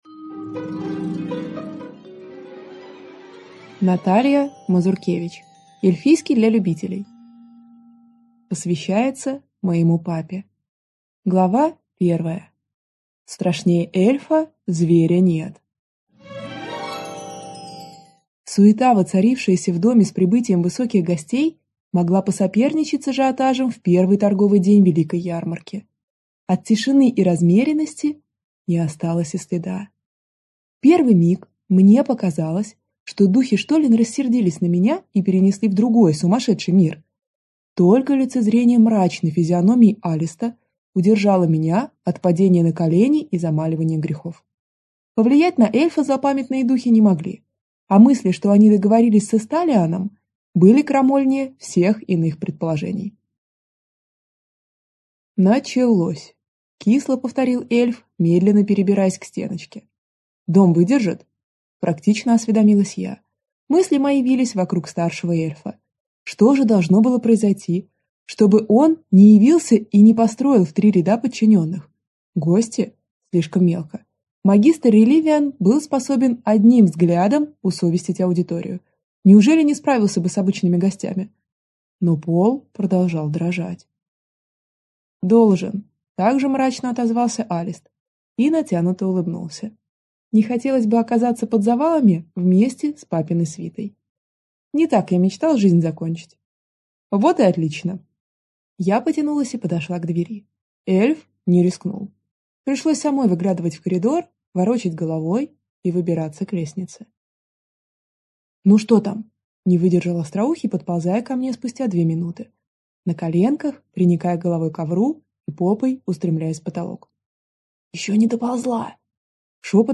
Аудиокнига Эльфийский для любителей | Библиотека аудиокниг
Прослушать и бесплатно скачать фрагмент аудиокниги